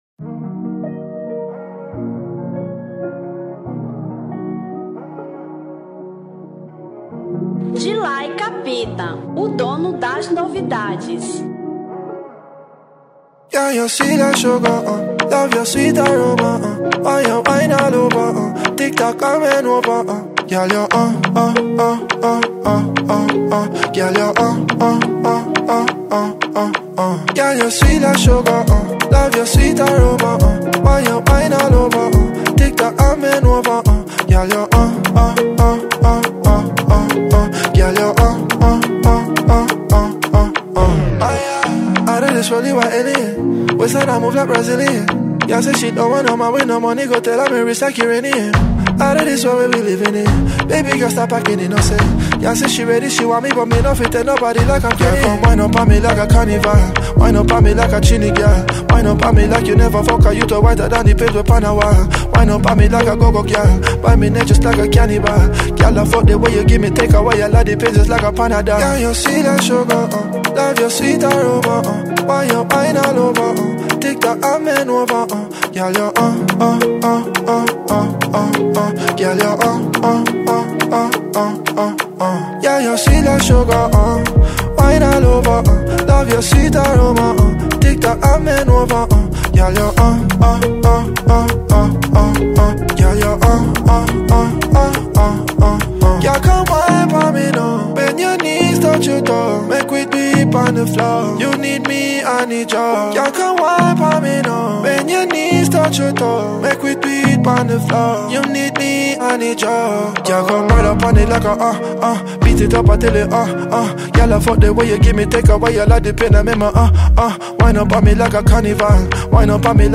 Afro Trap 2025